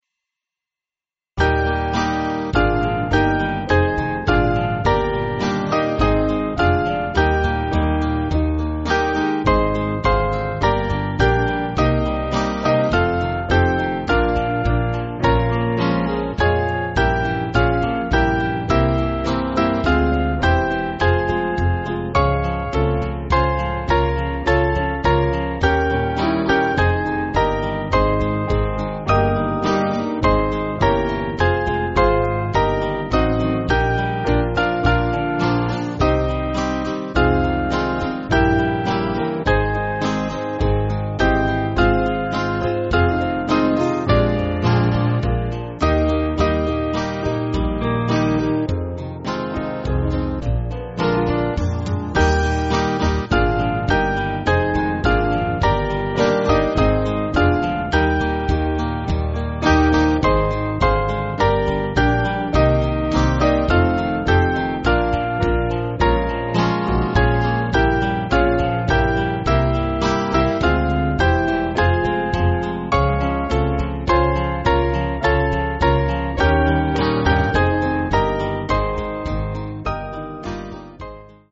Small Band
(CM)   4/Eb